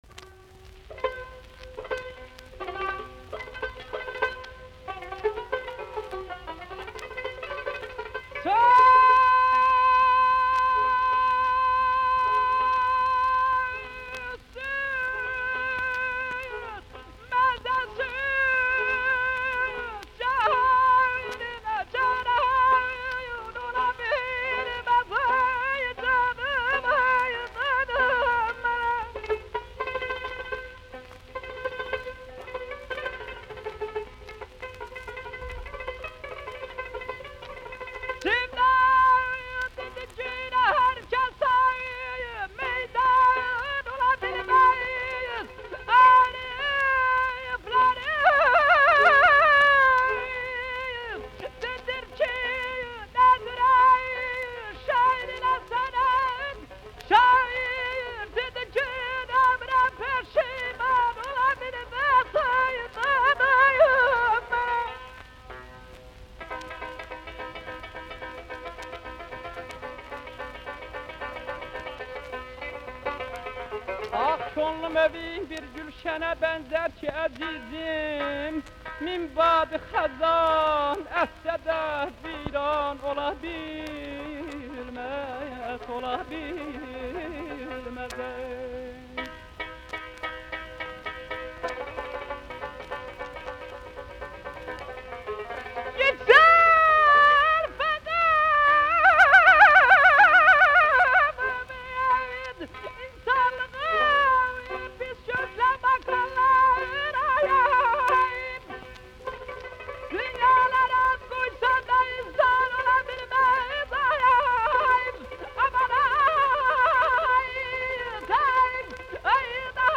From Azerbaijan. Folk songs with tar.